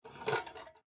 Lantern_Draw.wav